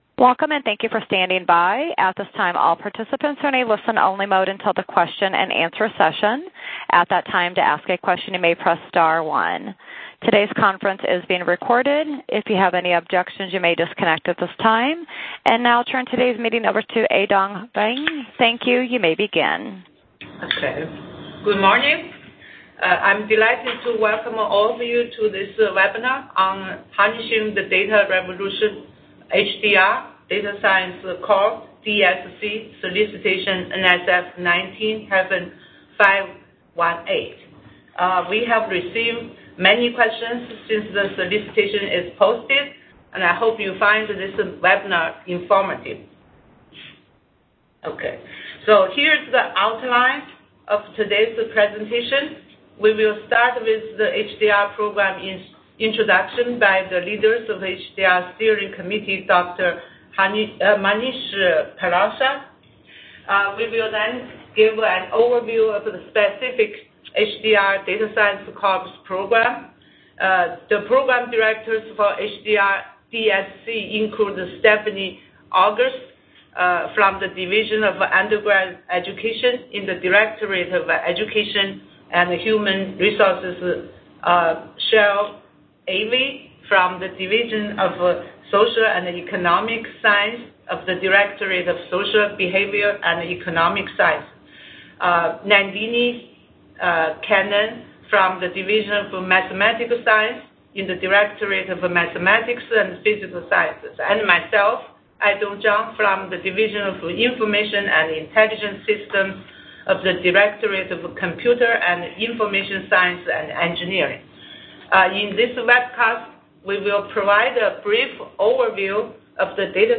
Dec 17th - 10:30am - Program Webinar